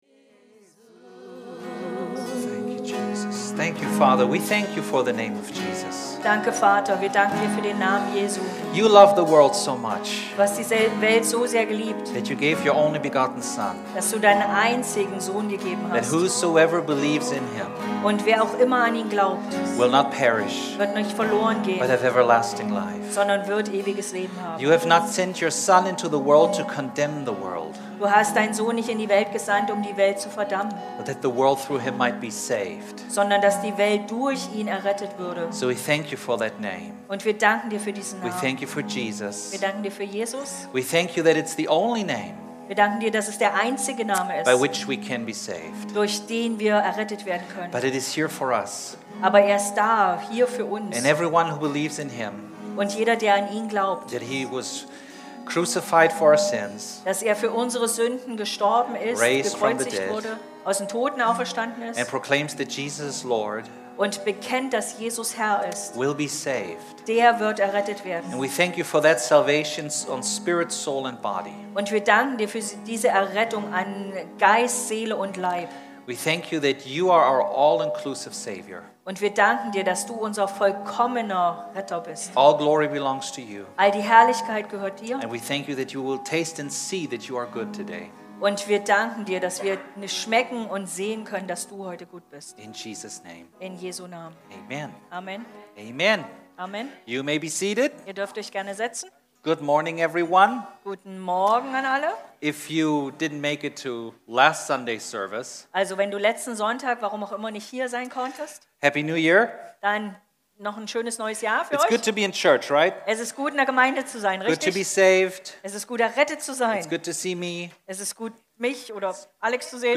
Wir hatten Probleme mit der Technik beim Livestreaming. Daher ist die Predigt nicht vollständig aufgezeichnet.
Therefore the sermon is not recorded fully.